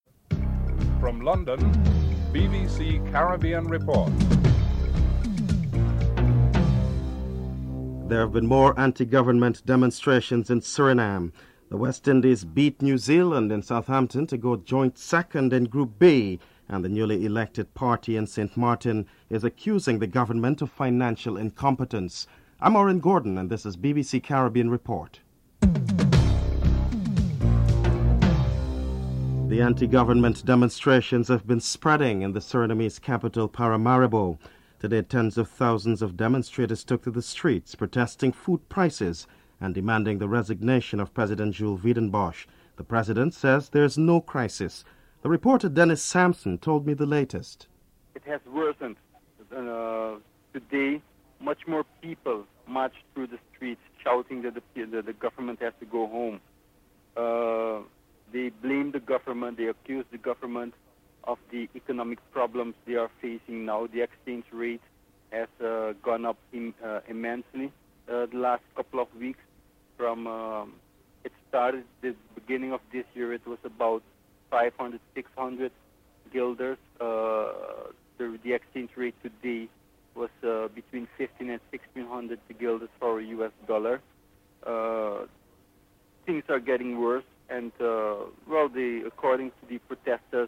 Dominica Prime Minister Edison James speaks on the delay in disbursement of funds.
dc.typeRecording, oralen_US